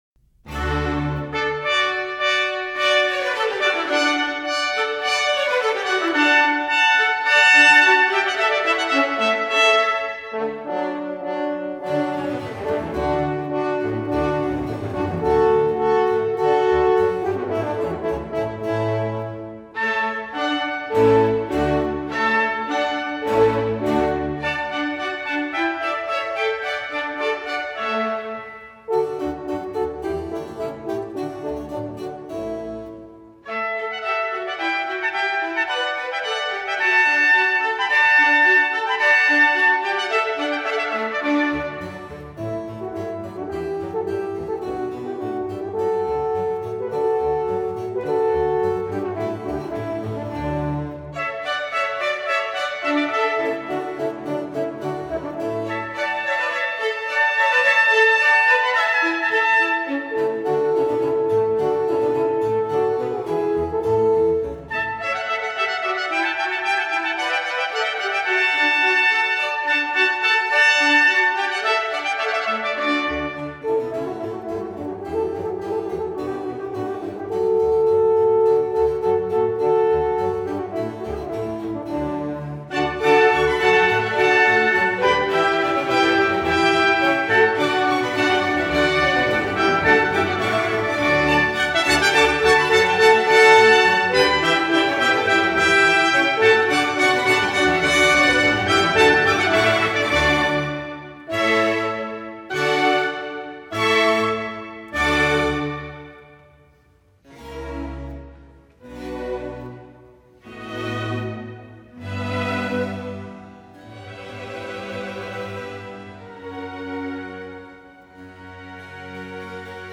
快板
这是一个欢庆、进行曲式的乐章，其中成对的小号和圆号相互呼应，色彩十分绚丽。